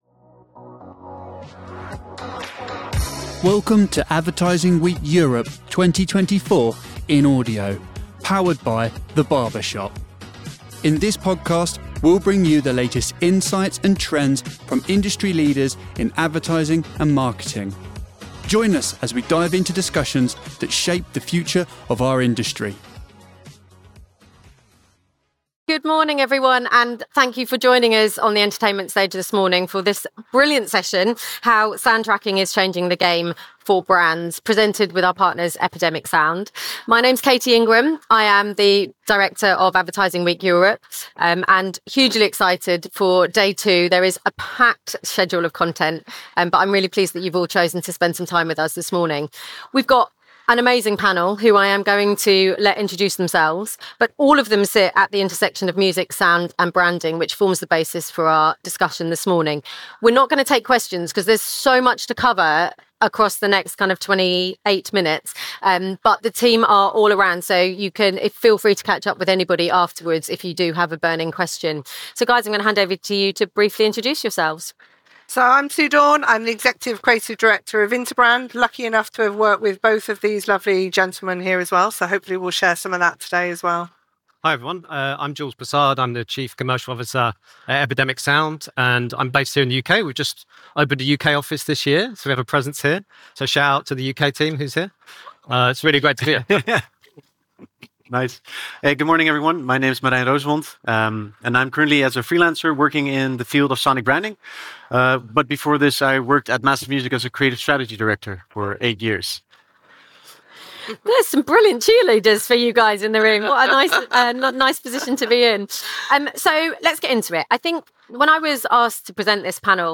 Discover how soundtracking is revolutionising content strategies for brands. This session, led by industry experts, discusses the importance of integrating sound into branding to enhance consumer engagement and create memorable experiences. Learn how brands can leverage sound to tell compelling stories and build deeper connections with their audience.